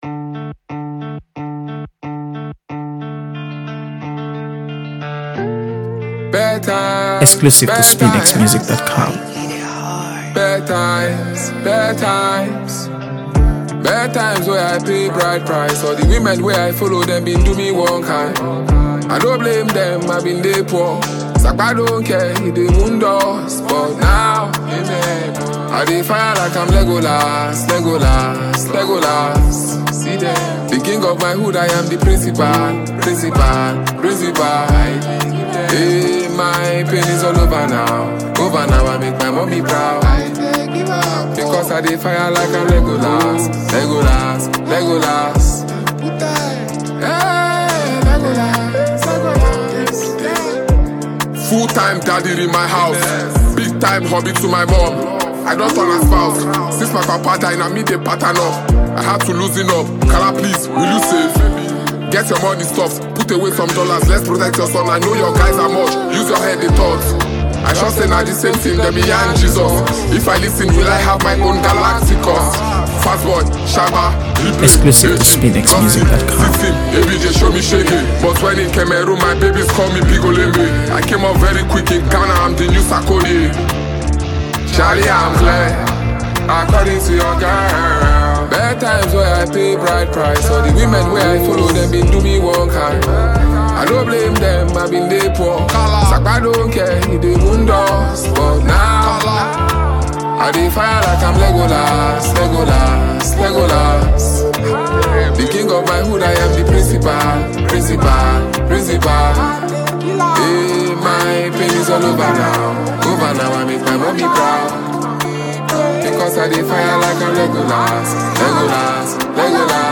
AfroBeats | AfroBeats songs
Nigerian rap
Armed with razor-sharp delivery and unmistakable swagger
The track’s hard-hitting energy is matched by its production
crisp, cinematic soundscape